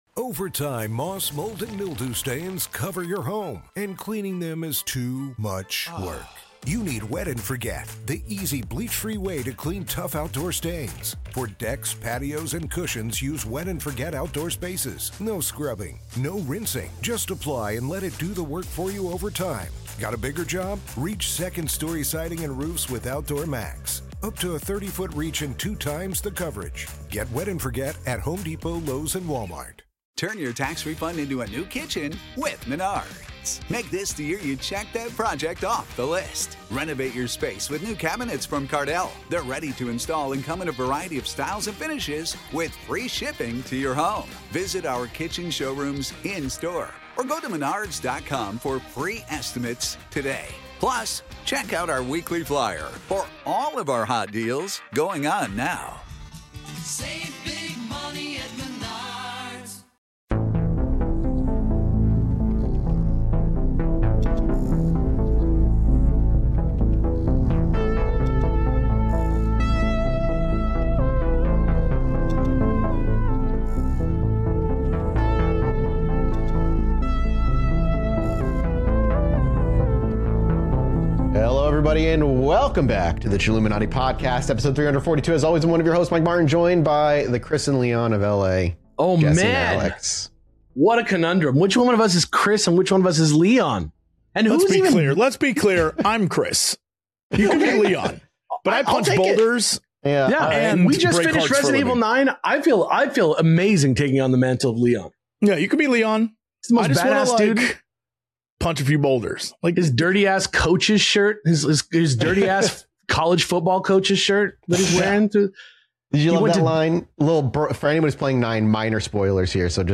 The boys read some listeners stories.